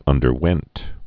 (ŭndər-wĕnt)